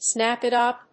snáp it úp
発音